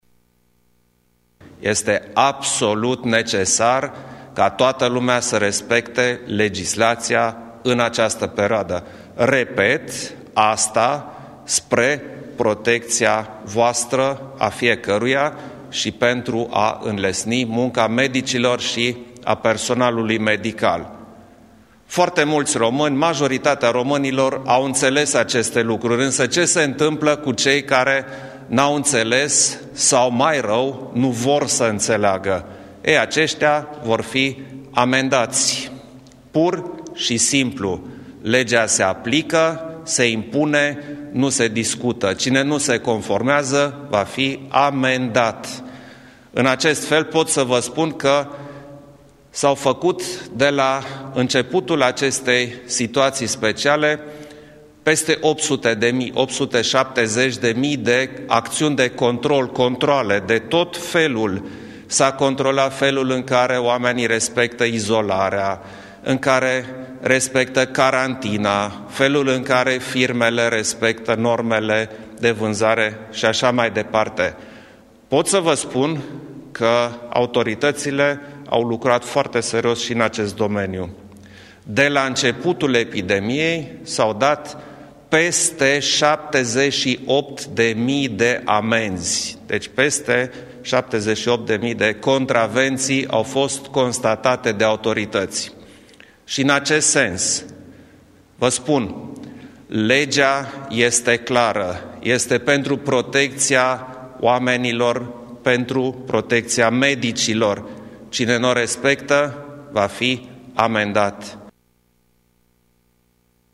Declarațiile au fost realizate la finalul unei întâlniri de evaluarea măsurilor împotriva virusului COVID 19 care a avut loc la Palatul Cotroceni cu miniștrii Apărării și Internelor.
Președintele Klaus Johannis: